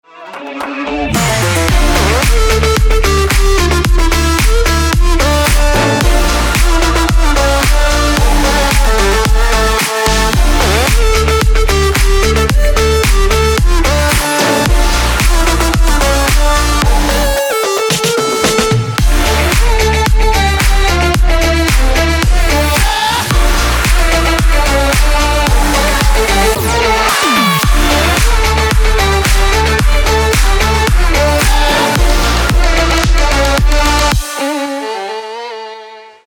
• Качество: 224, Stereo
Electronic
без слов
club
electro house
Melodic